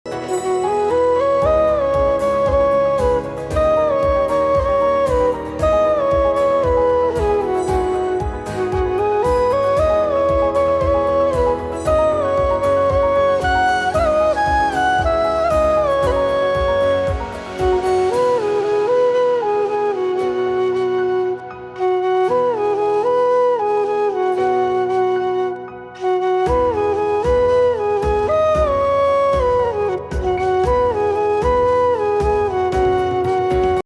flute
• Peaceful and soothing instrumental music
No, it is a pure instrumental version without vocals.